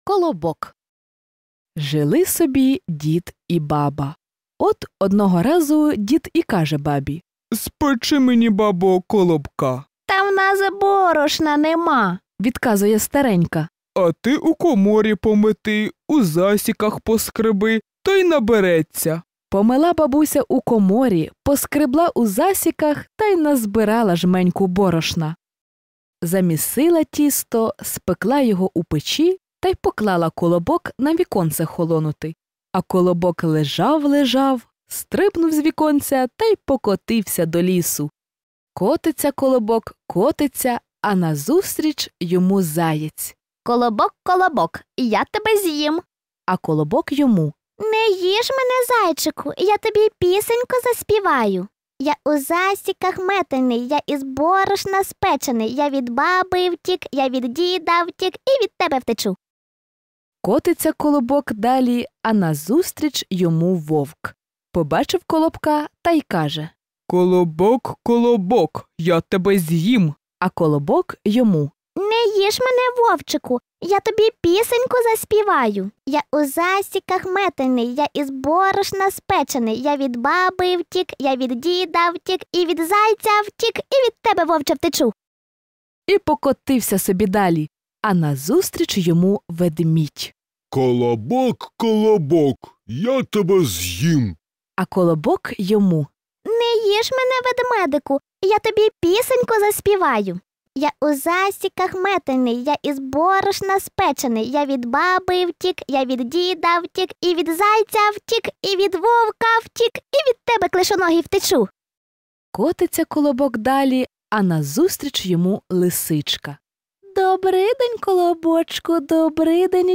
Аудіоказка “Колобок” українською – слухати та скачати безкоштовно в форматах MP3 і M4A
Аудіоказки для маленьких діточок: слухати і скачати